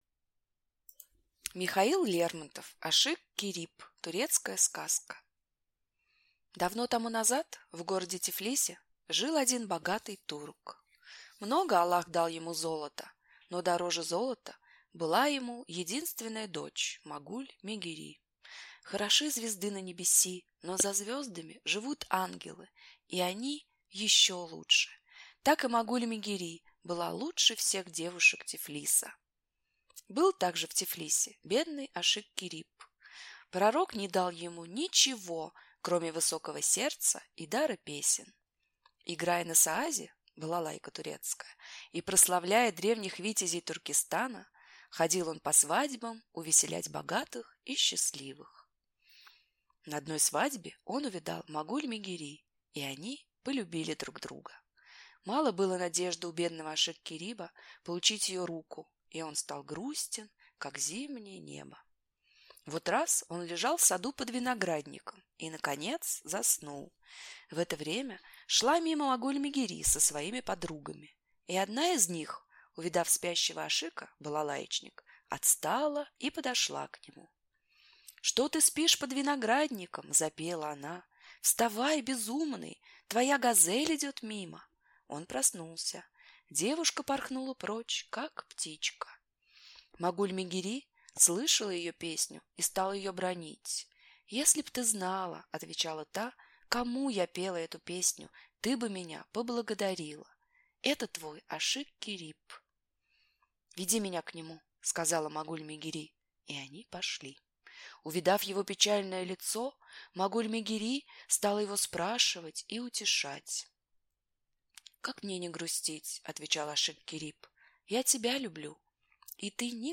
Аудиокнига Ашик-Кериб | Библиотека аудиокниг